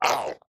assets / minecraft / sounds / mob / strider / hurt4.ogg
hurt4.ogg